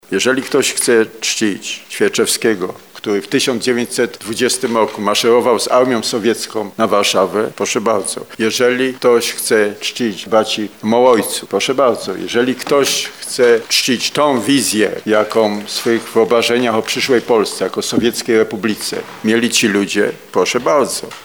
– Jeśli ktoś chce czcić pamięć dawnych patronów, droga wolna. Instytut Pamięci Narodowej musi jednak zamknąć sprawę dekomunizacji – dodaje prezes IPN doktor Jarosław Szarek.